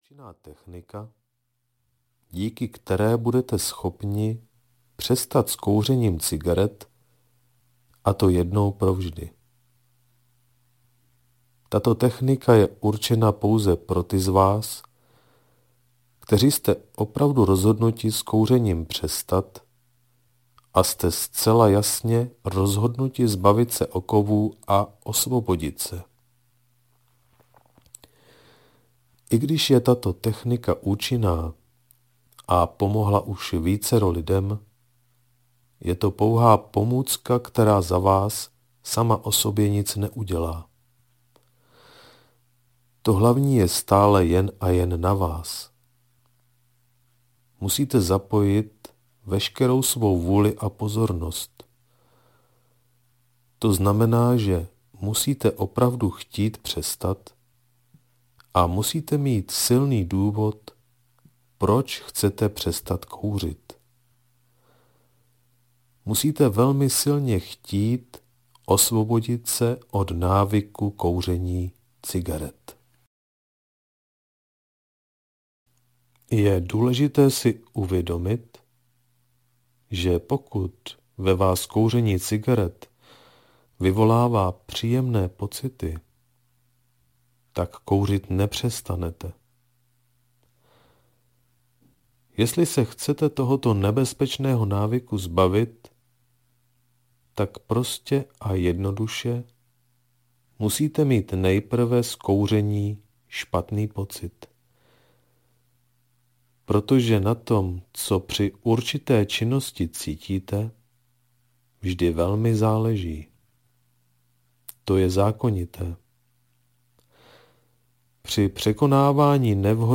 Stop kouření audiokniha
Ukázka z knihy